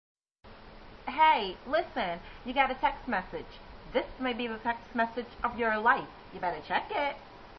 textmessage
Tags: funny alerts ring tones media jokes